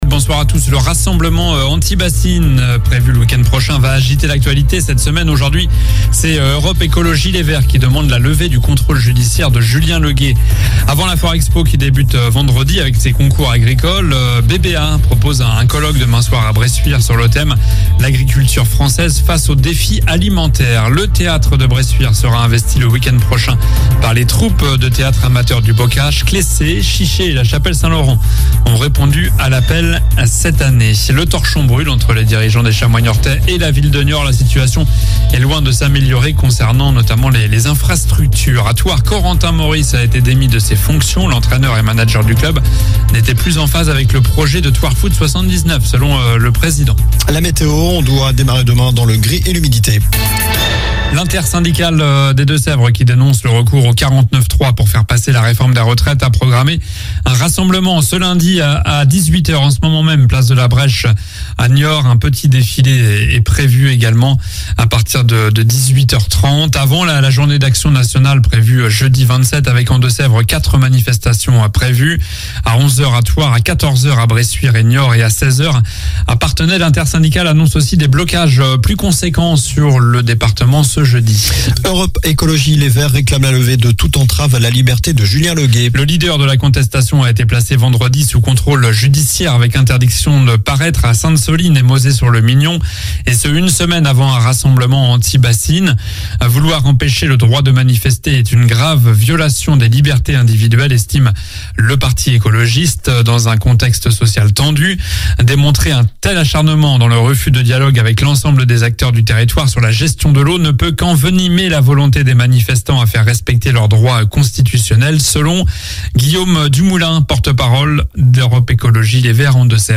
Journal du lundi 20 mars (soir)